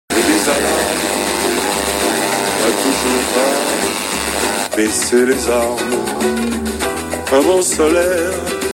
On entend très nettement les crépitements de l'onduleur sur la chaine HIFI.
Fichier audio mp3 - FM 107,7 MHz brouillée
sur la chaine HIFI avec un essai de coupure du panneau solaire à 4 secondes.
A environ 4 secondes on entend très nettement la différence !